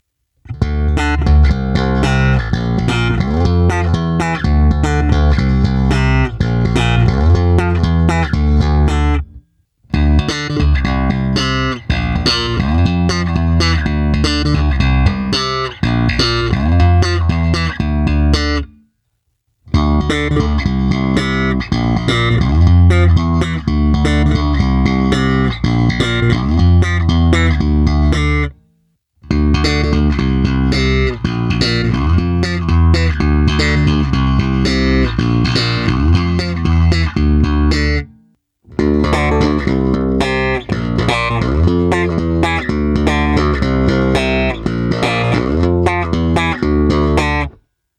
Bonusové nahrávky se simulací aparátu ve stejném pořadí jako výše (1-5)
Ukázka moderního zkreslení